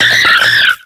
Cries
MINUN.ogg